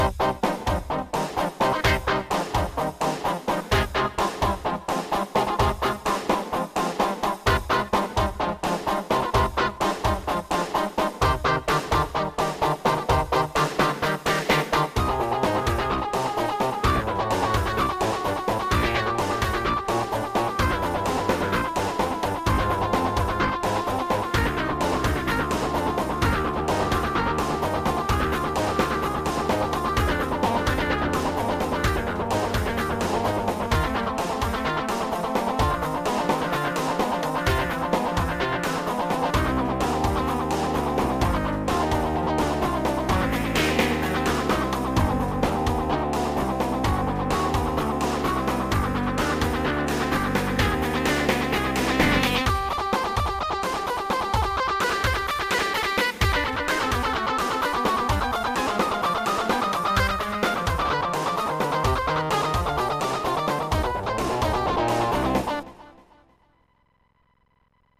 Είναι "fun - Heavy Metal - μπαρόκ" διασκευή από το 2ο Πρελούδιο σε Ντο ελλάσονα του J.S.Bach.